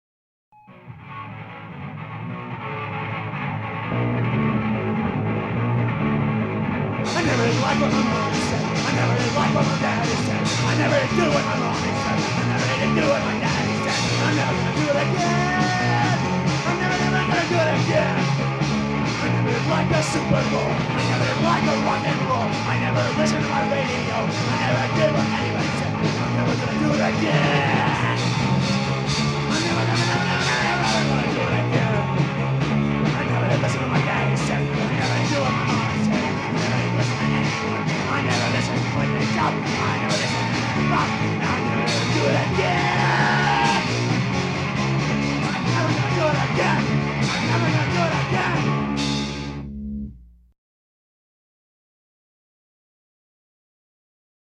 in the land of Tucson punk rock